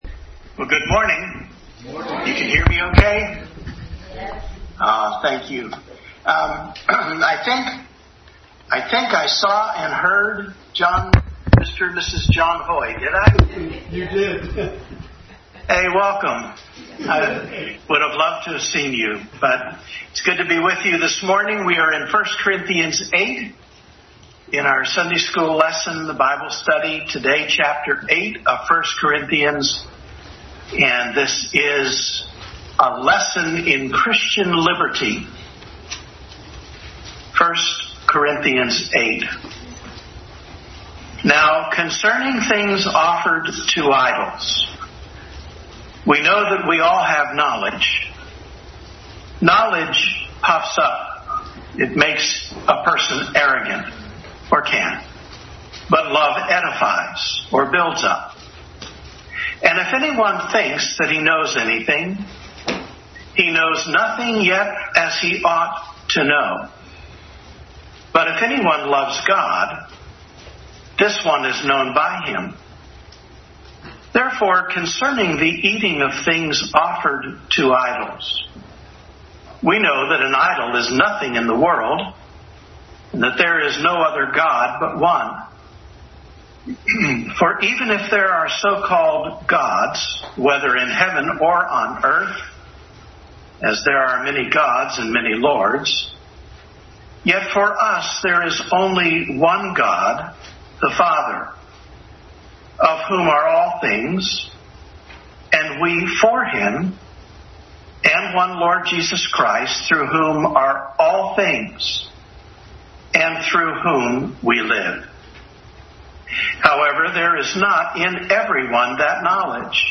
Adult Sunday School Class continued study in 1 Corinthians.
1 Corinthians 8:1-13 Service Type: Sunday School Adult Sunday School Class continued study in 1 Corinthians.